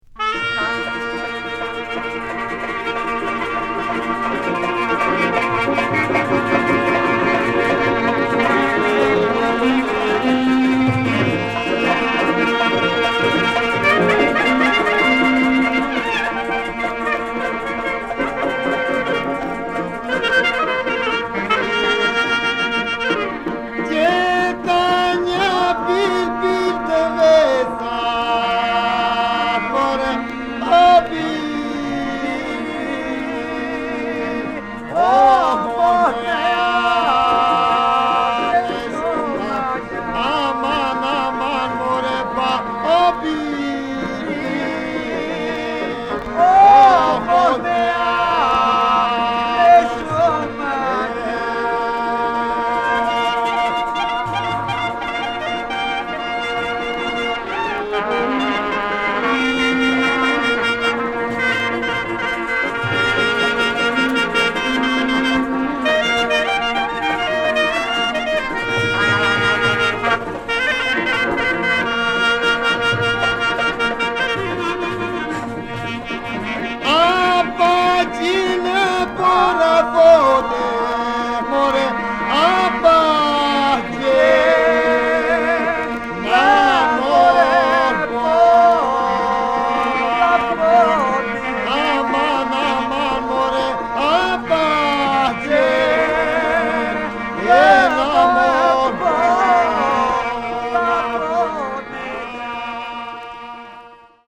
旧ユーゴスラヴィアのマケドニアで録音されたアルバニア人の音楽。
特異な多声音楽文化を持つことで知られるアルバニア人の中でも、異郷風情まんてんのポリフォニーを奏でるトスク人の音楽にフォーカス。試聴は、アルバニアの永久音楽劇場の異名を進呈したい伝統の集団合奏から。各々漫然と即興を繰り広げているようでありながら、全体がひと続きのうねりとなってゆらゆらと揺れ動く、高度なモード感覚で空間の位相を自在に操る圧巻の演奏。
キーワード：Ocora　現地録り　ドローン